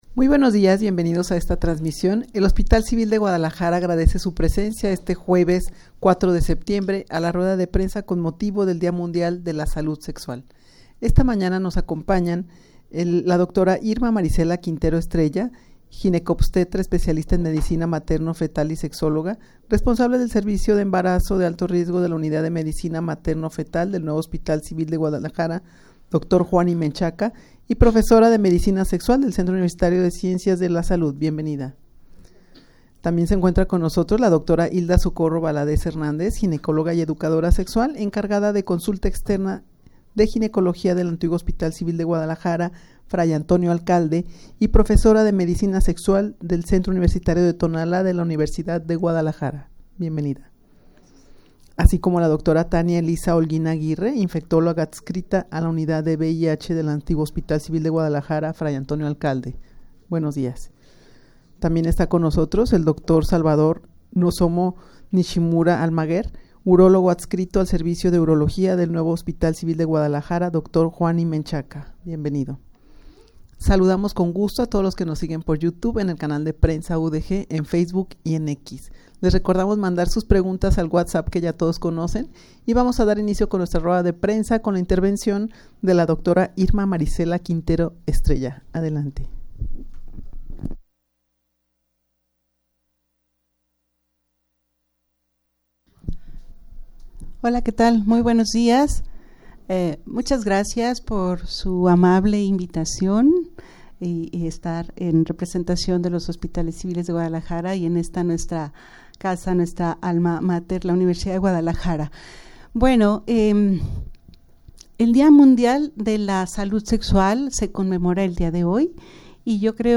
Audio de la Rueda de Prensa
rueda-de-prensa-con-motivo-del-dia-mundial-de-la-salud-sexual_0.mp3